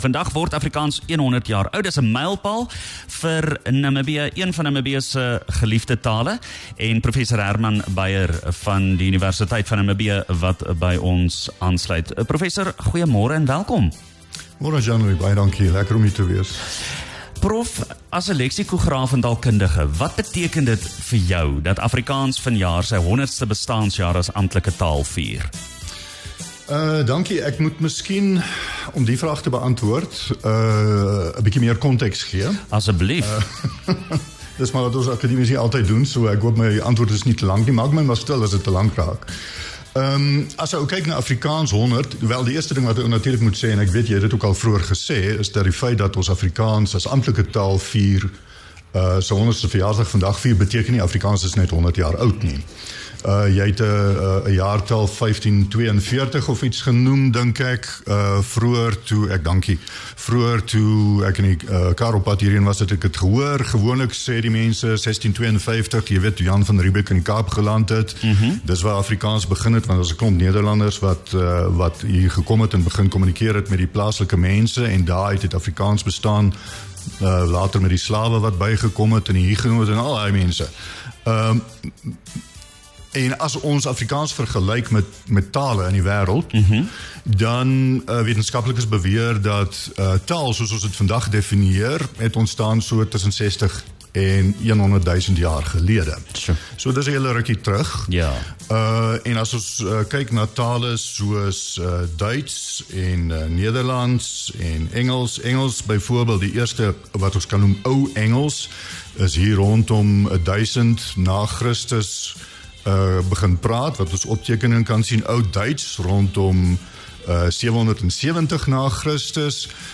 Hulle het gesels oor die mylpaal: Afrikaans is 100 jaar oud.